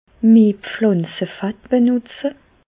Ville Prononciation 67 Reichshoffen